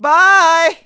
Here are a couple of bonus sound effects that ALWAYS make me laugh:
Byyeee!
WW_Beedle_Bye.wav